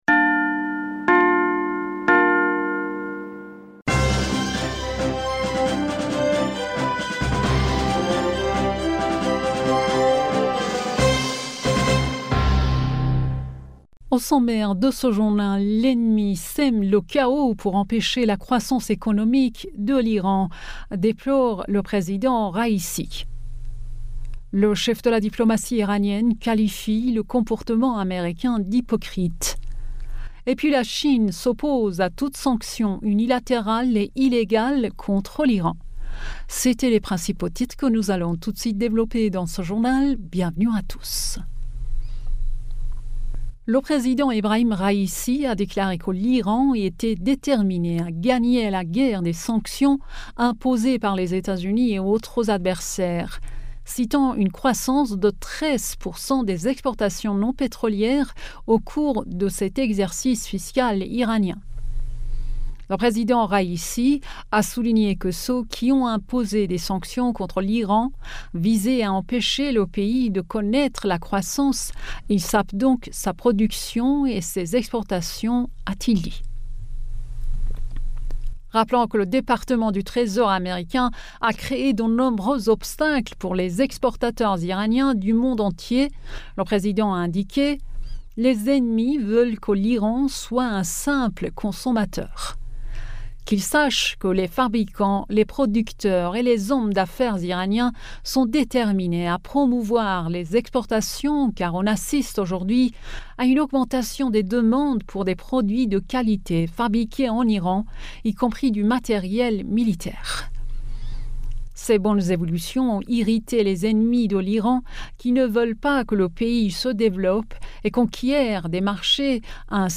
Bulletin d'information Du 23 Octobre